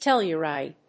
/ˈtɛljɝˌaɪd(米国英語), ˈteljɜ:ˌaɪd(英国英語)/